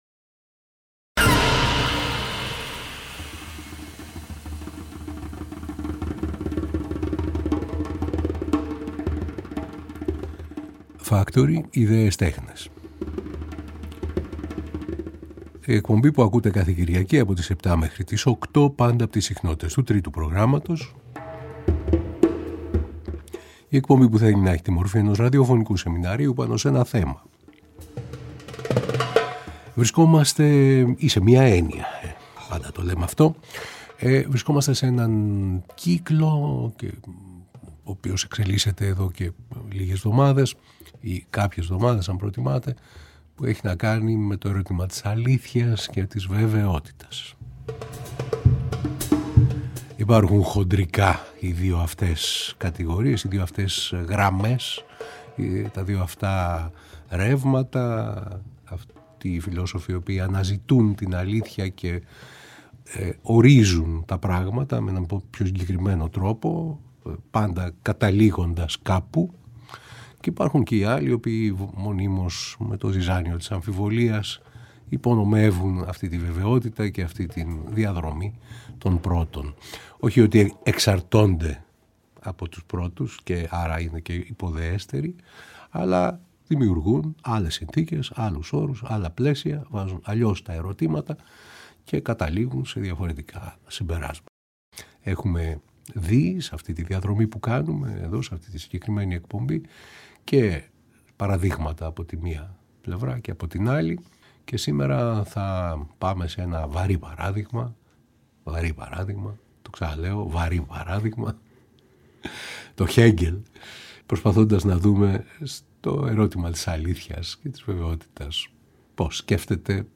Αλήθεια (ή αλήθειες) και Βεβαιότητα (ή βεβαιότητες) – Εκπομπή 7η Νέος Κύκλος Εκπομπών Επεισόδιο 7ο: Αλήθεια , γνώση και βεβαιότητα στον Χέγκελ . Στο στούντιο ο διδάκτορας Φιλοσοφίας του Πανεπιστημίου της Σορβόνης